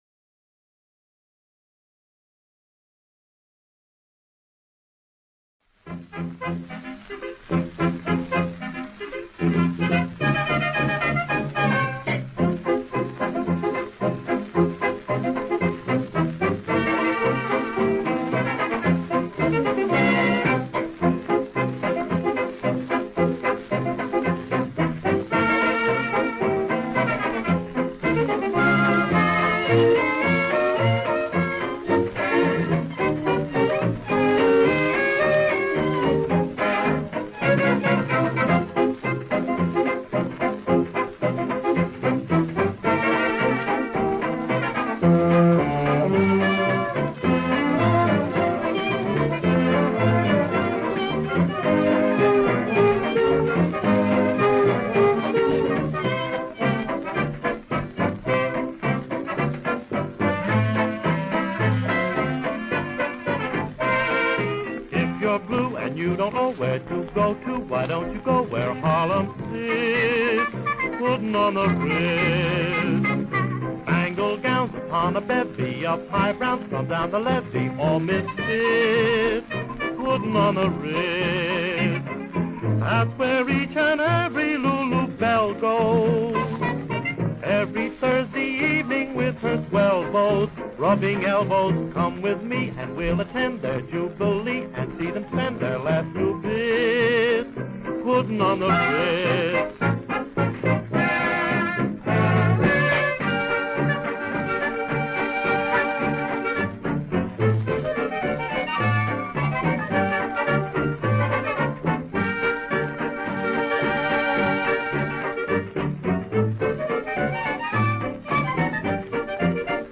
Soothing honeyed liquid gold.
Broadcast from the Hudson studio.